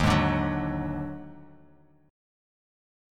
D#mM7bb5 chord